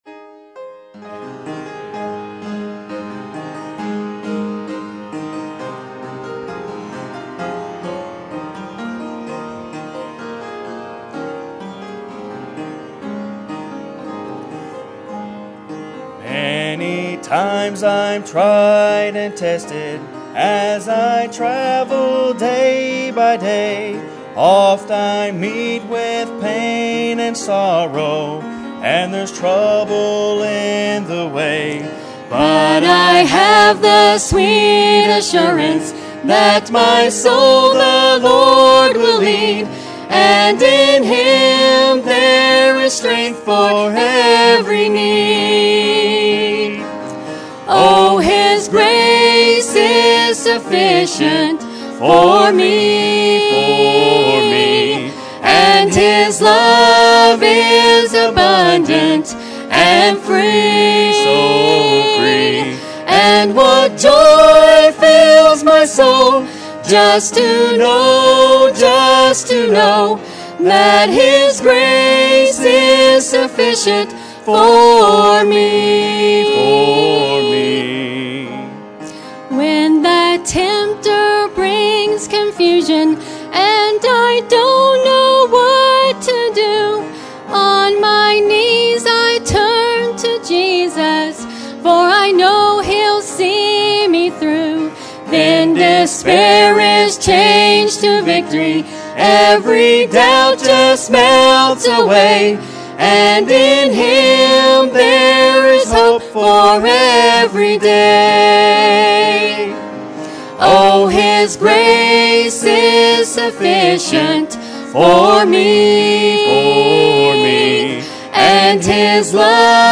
Mixed Duet